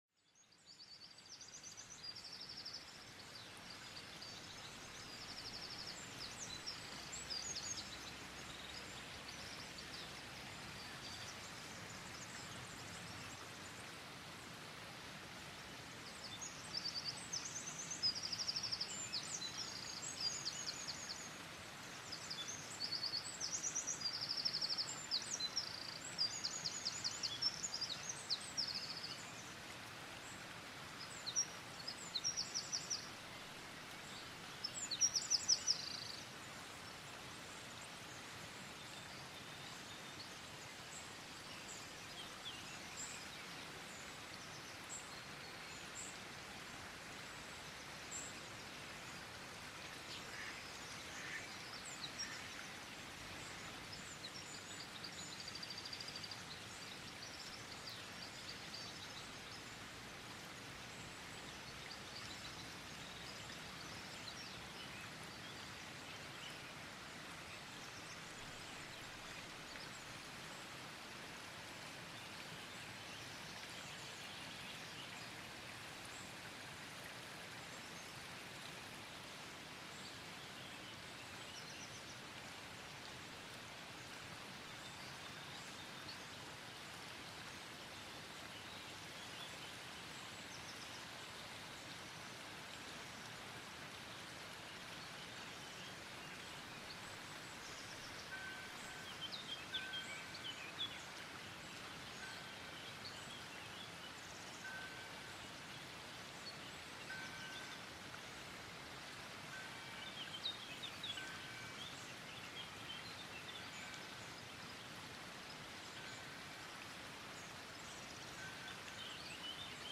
Frühlingsfluss-Sonnenstrahlen: Klarer Strom + Licht = pure Entspannung